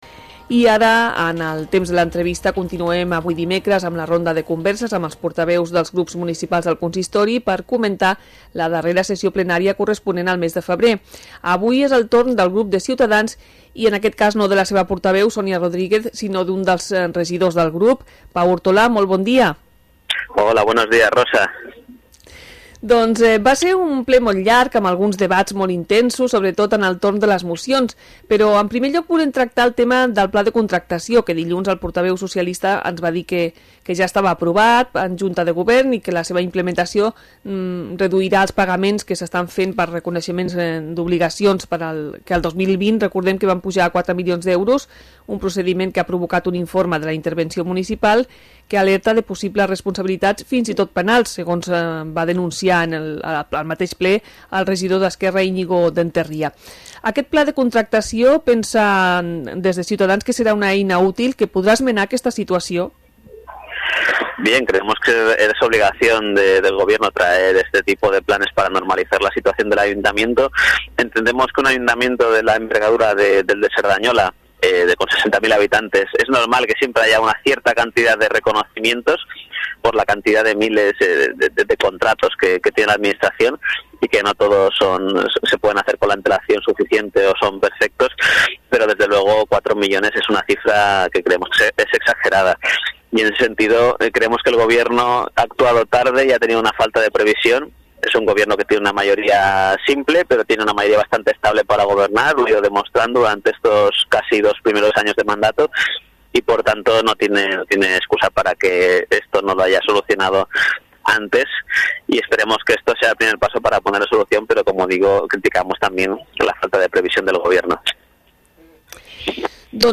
Entrevista-Pau-Ortolà-Cs-Ple-febrer.mp3